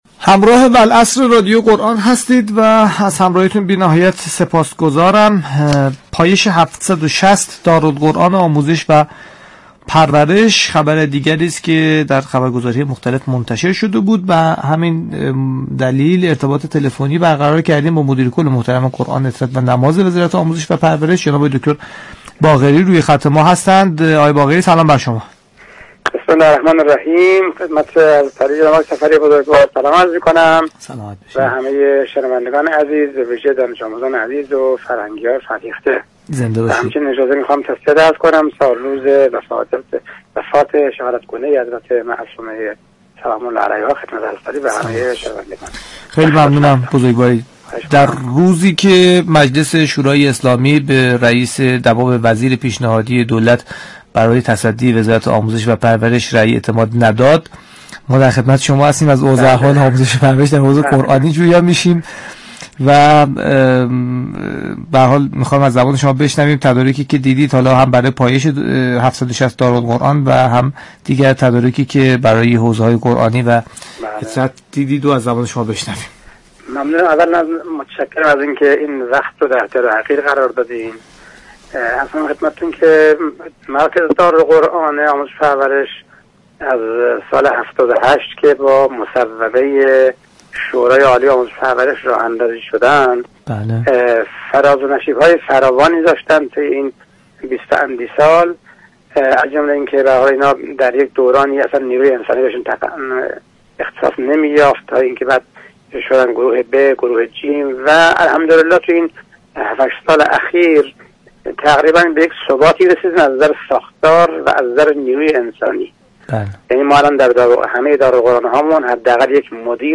به گزارش پایگاه اطلاع رسانی رادیو قرآن ؛ میكائیل باقری مدیركل قرآن عترت و نماز وزارت آموزش و پرورش در گفتگو با برنامه والعصر 25 آبان گفت : پایش 760 مركز دارالقرآن الكریم این هفته به ایستگاه پایانی خود می رسد .